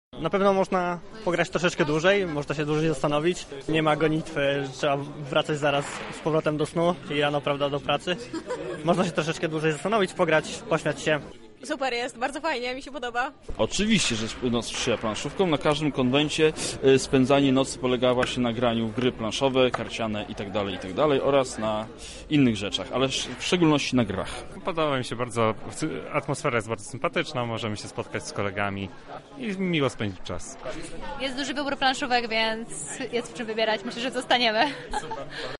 Fani planszówek spędzili ten czas w Centrum Spotkania Kultur.
Festiwal oferuje szeroki wybór gier do wypożyczenia i zagrania. W ostatni piątek miało miejsce Analogowe „Nocne” Granie.